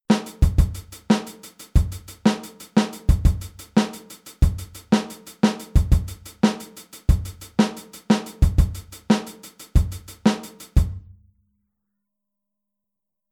Aufteilung linke und rechte Hand auf HiHat und Snare
Im Grunde genommen ist dieser Groove schon fast ein reiner Offbeat an sich. Beginnt mit Snare und alle folgenden Events fast ausschließlich auf dem UND.